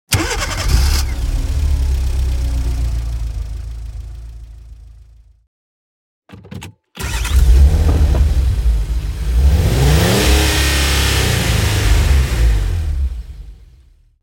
جلوه های صوتی
دانلود آهنگ ماشین 23 از افکت صوتی حمل و نقل
دانلود صدای ماشین 23 از ساعد نیوز با لینک مستقیم و کیفیت بالا
برچسب: دانلود آهنگ های افکت صوتی حمل و نقل دانلود آلبوم صدای انواع ماشین از افکت صوتی حمل و نقل